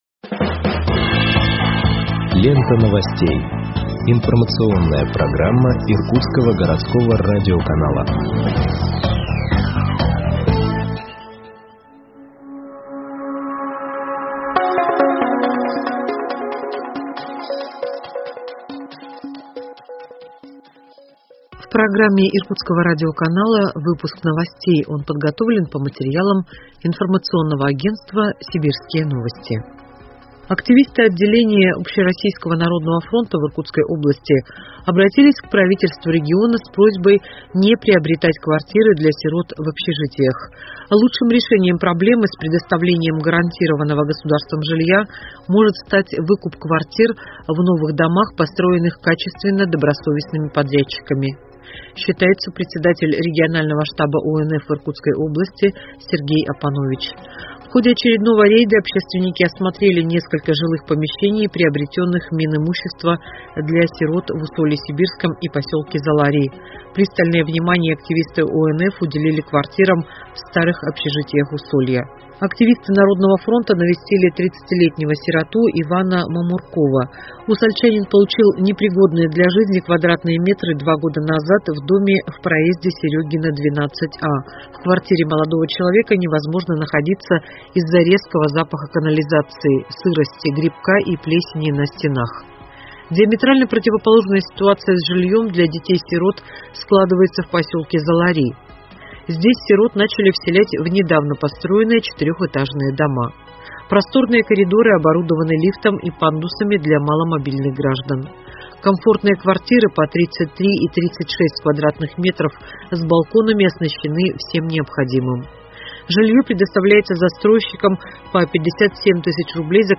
Выпуск новостей в подкастах газеты Иркутск от 26.10.2021 № 1